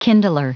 Prononciation du mot kindler en anglais (fichier audio)
Prononciation du mot : kindler